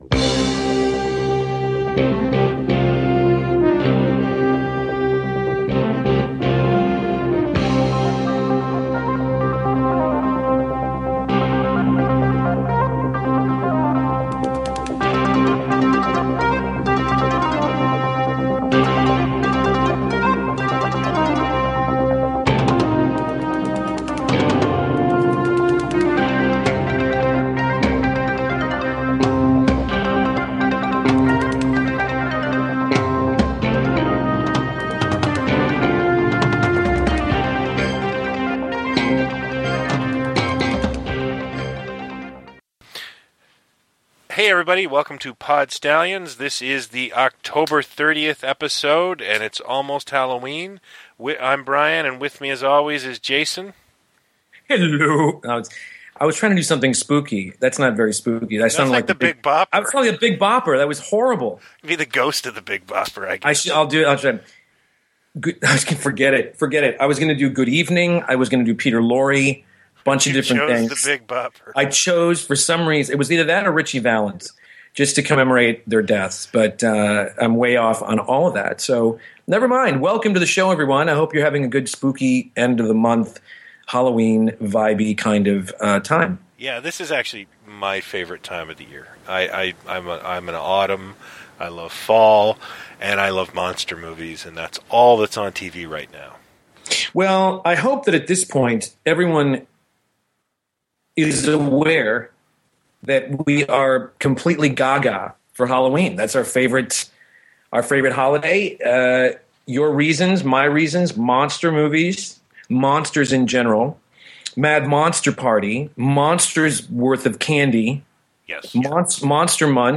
Episode 37 is our first “lost episode” as we had to re-record half of it after a computer glitch (sorry), so we know it’s not October.